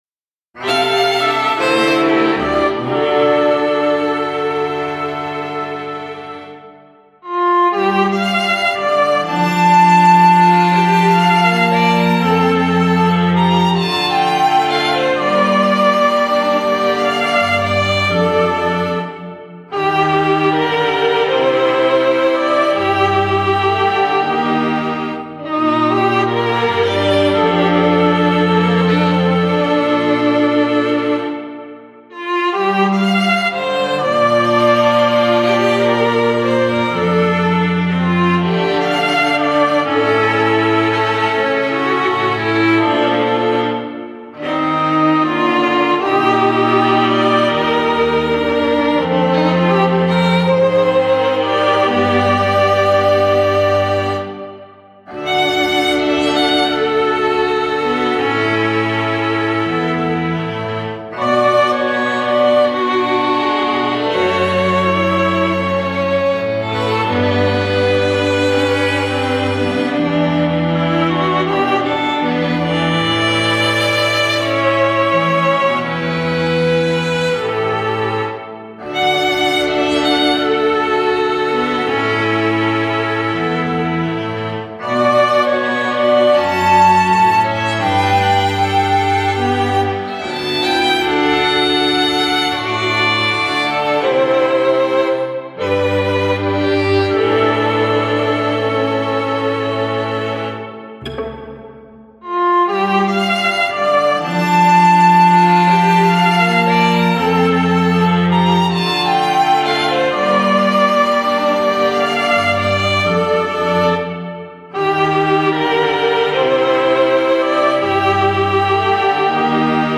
弦楽四重奏です。
傾向 　弦楽四重奏